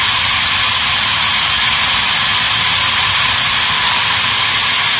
turbinegasRunning.ogg